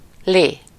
Ääntäminen
IPA : /ˈmʌn.i/